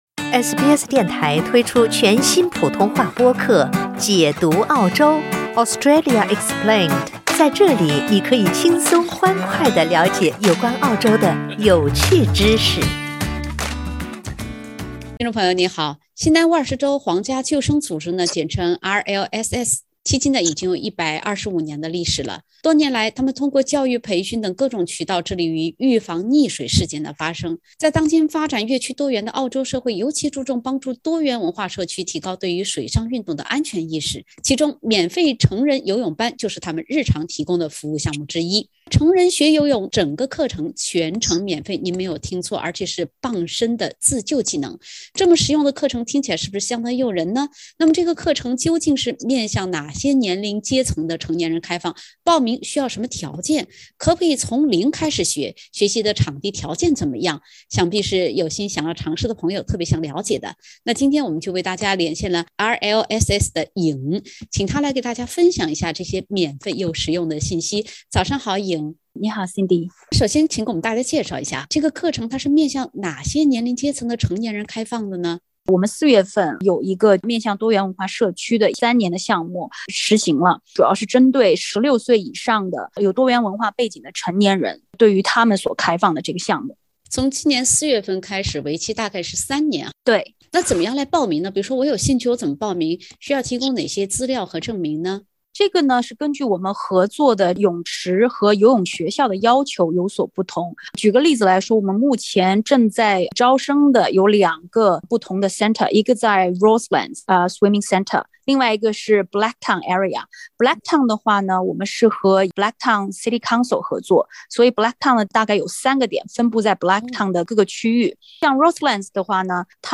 新南威尔士州皇家救生组织RLSS近期向多元文化社区的成年人开放免费学习游泳课程。（点击封面图片，收听完整访谈）